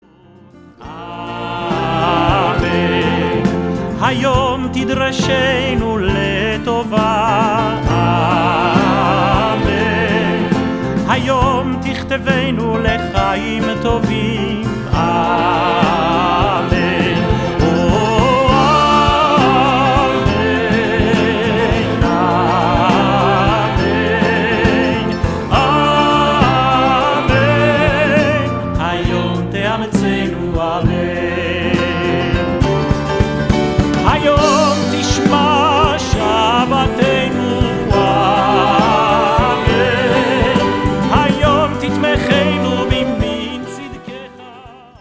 a choir and instruments
organist